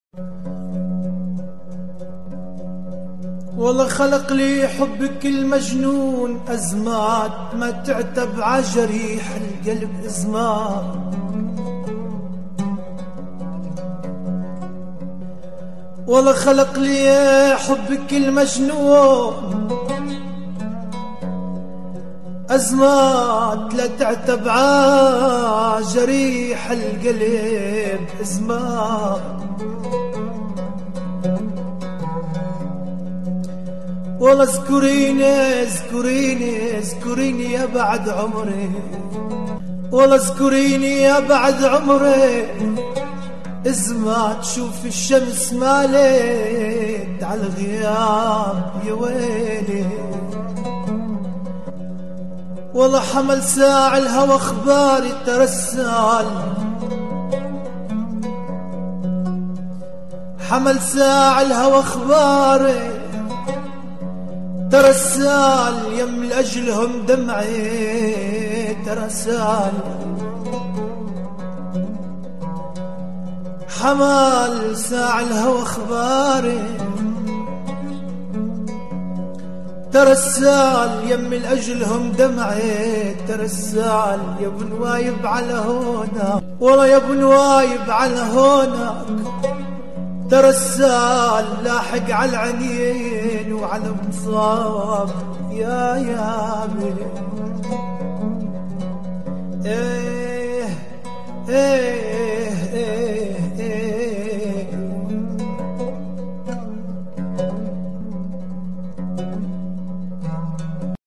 اغاني سوريه